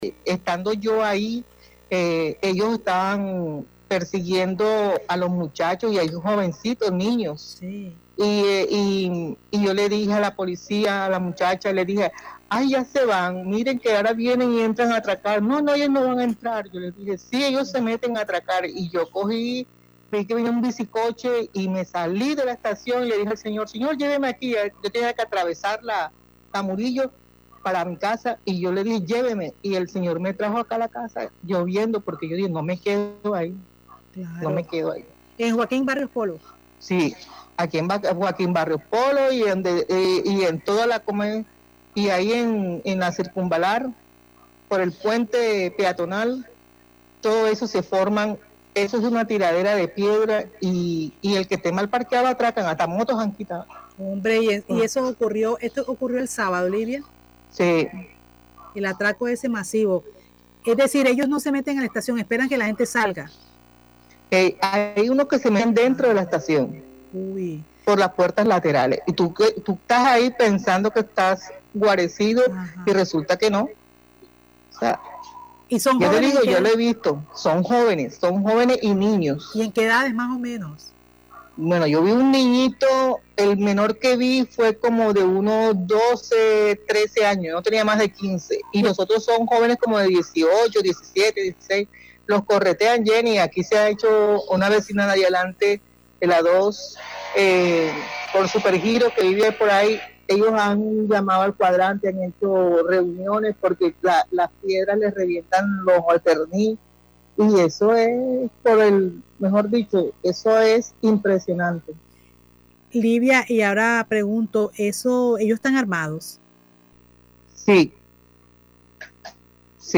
Oyente.mp3